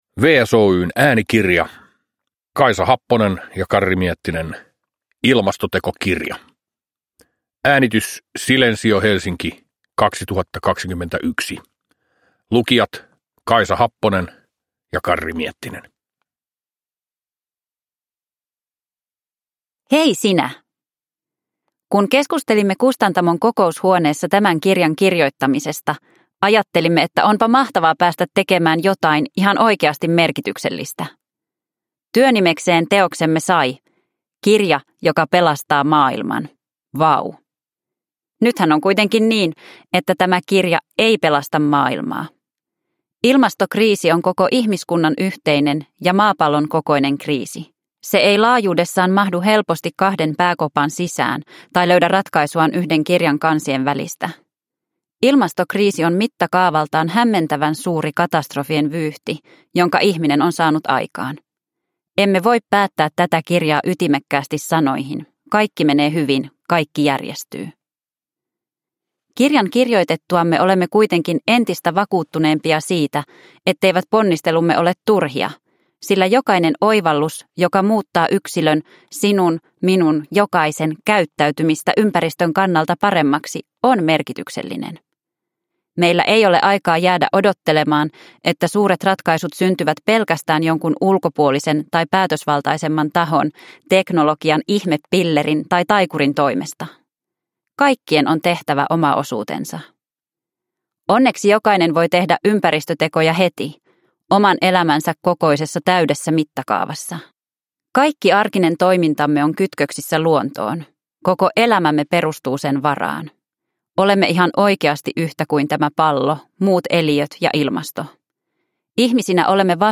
Ilmastotekokirja – Ljudbok – Laddas ner